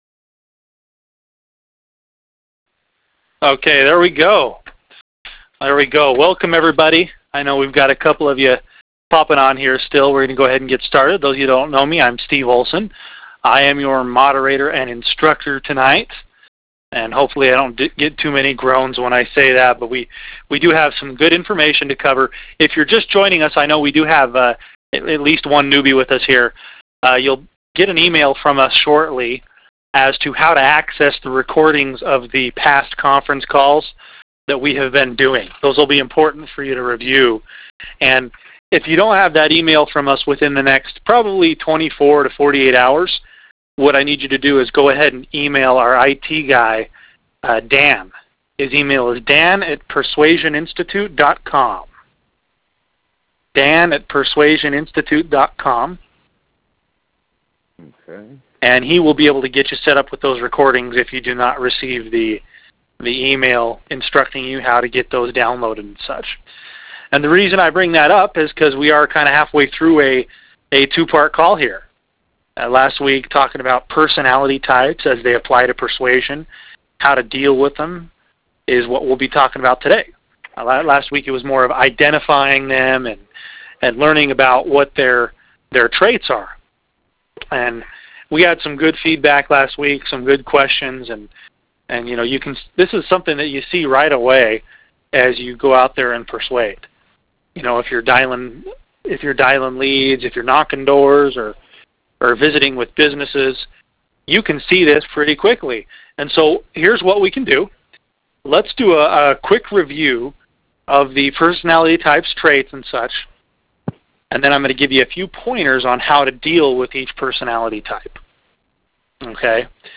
‹ 4 Keys to Negotiation 4 Rs #1 – Reason › Posted in Conference Calls